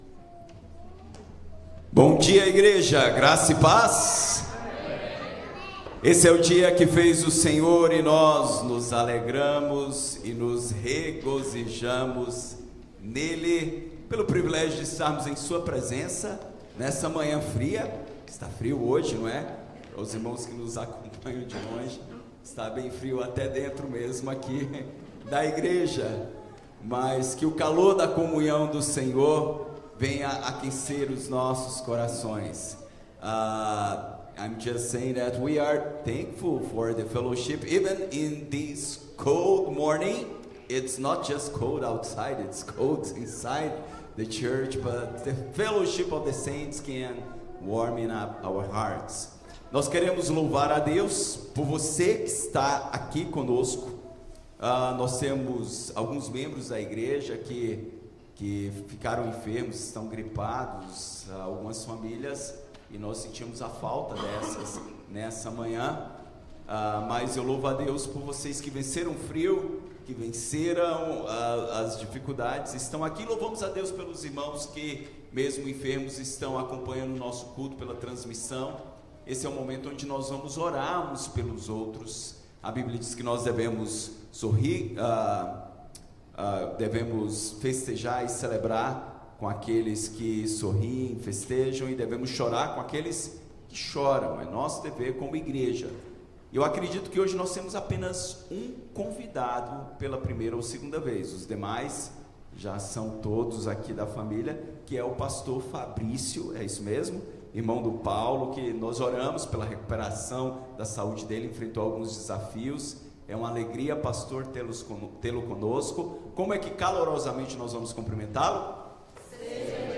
Sermons from Huntingdon Valley Presbyterian Church